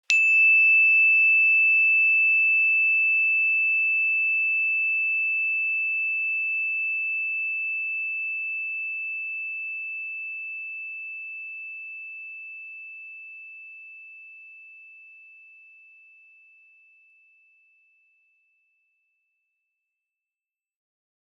energychime_wood-E6-ff.wav